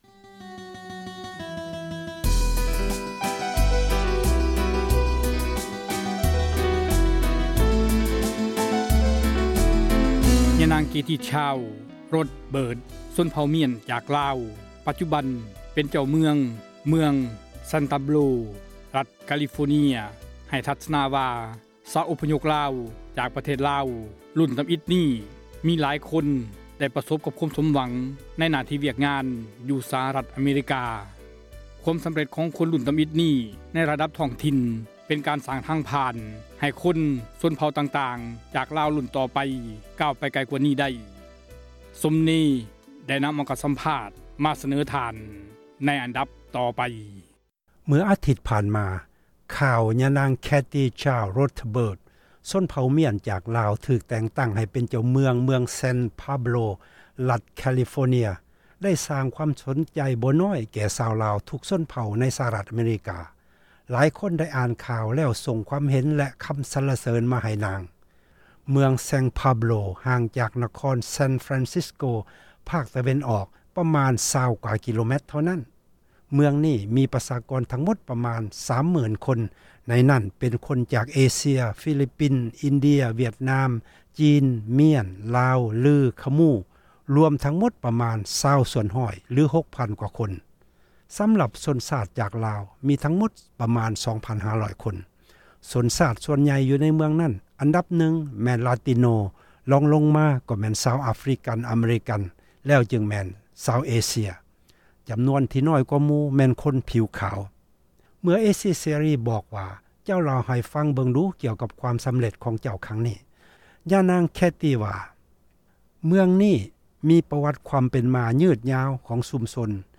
ສໍາພາດຍິງລາວເຜົ່າ "ມຽນ"